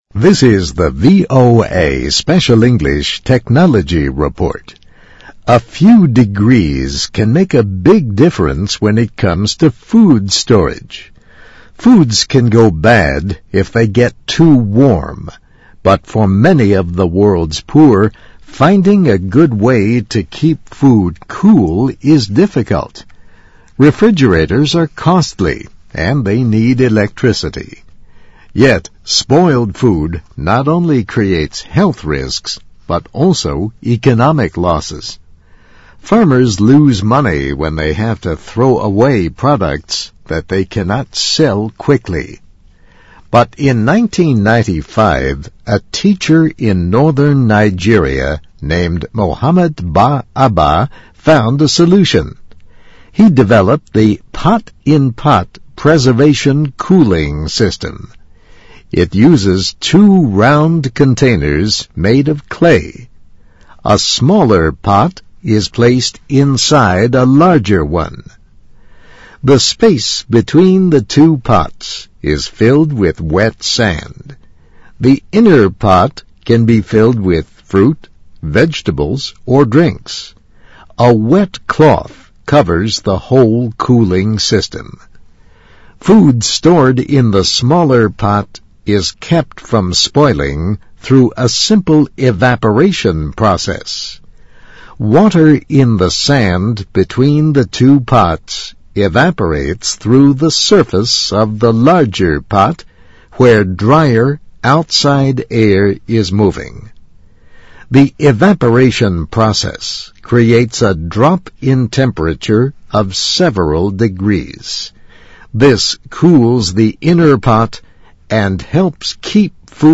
VOA慢速英语2011--A Cool Way to Keep Food From Spoiling 听力文件下载—在线英语听力室